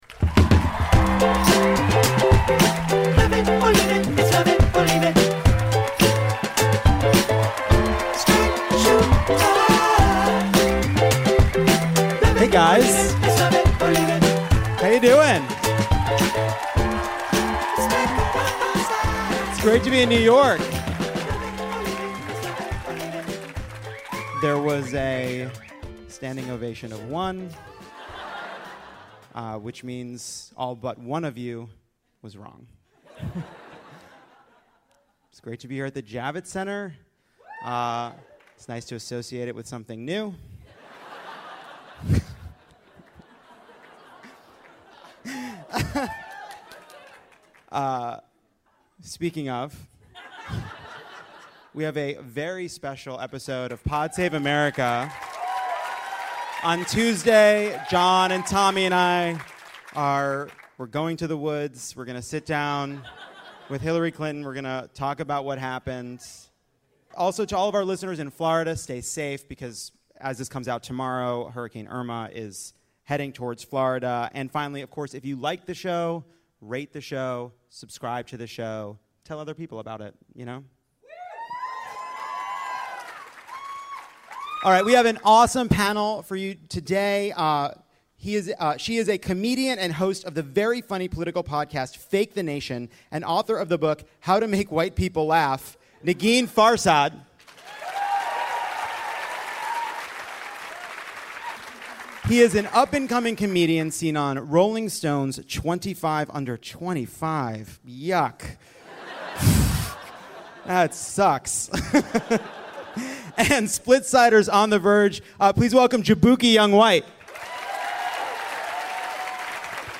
Sasheer Zamata, Negin Farsad, and Jaboukie Young-White join Jon to break down the week's news. Plus a dramatic reading you won't want to miss. Recorded live at the Now Hear This Podcast Festival in New York.